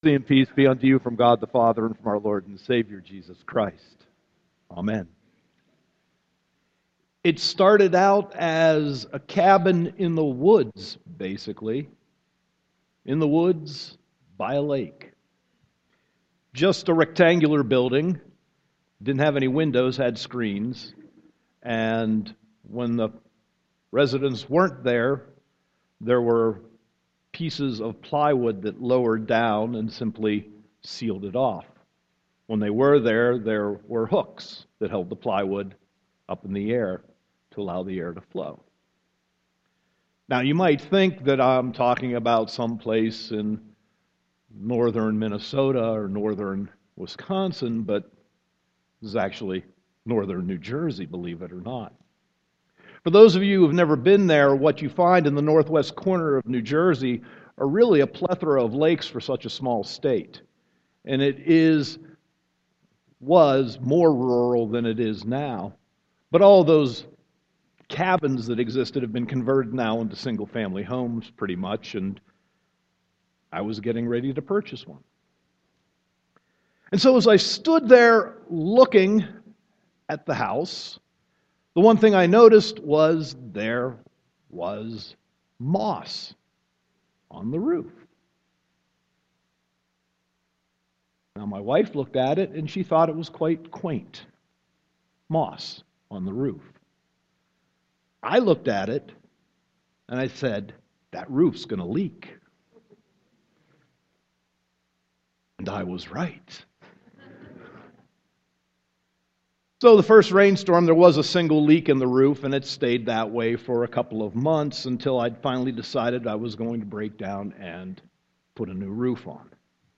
Sermon 1.25.2015